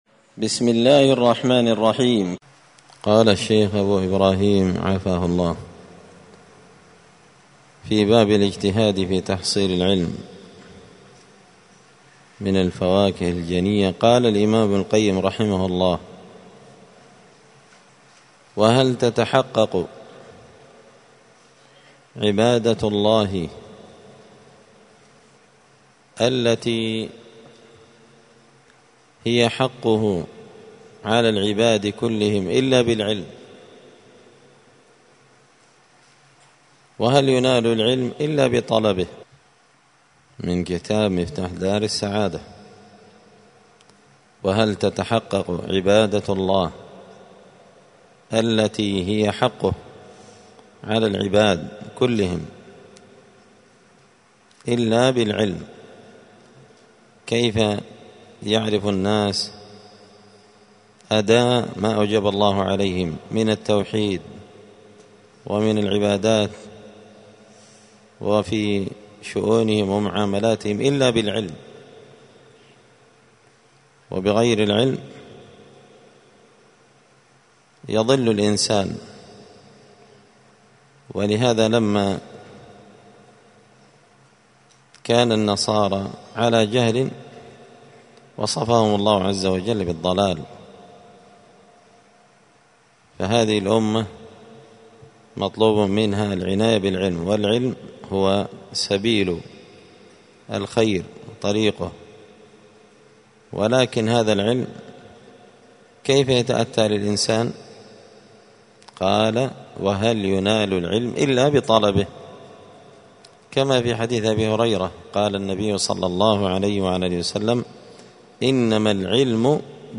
دار الحديث السلفية بمسجد الفرقان بقشن المهرة اليمن
السبت 7 شعبان 1445 هــــ | الدروس، الفواكه الجنية من الآثار السلفية، دروس الآداب | شارك بتعليقك | 22 المشاهدات